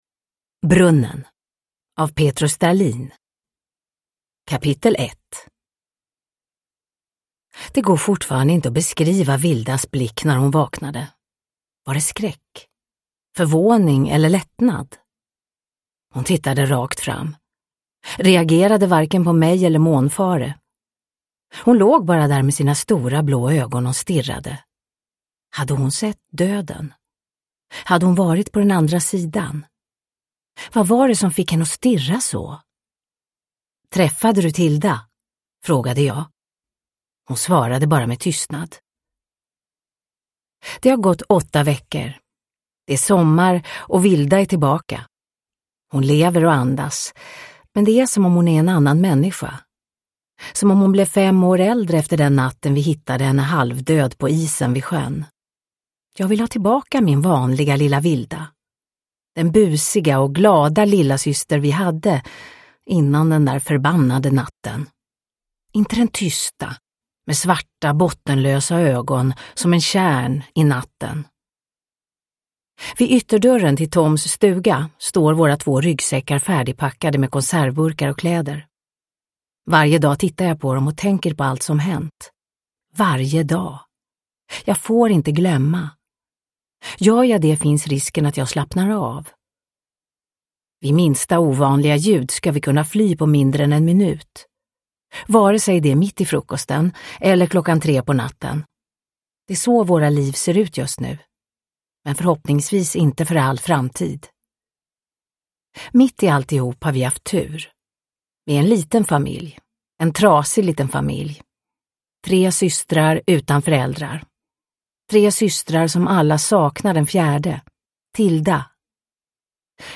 Brunnen – Ljudbok
Uppläsare: Marie Richardson